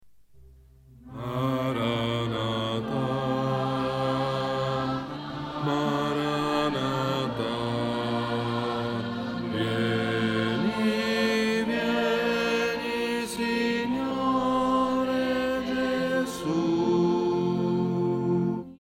basso.mp3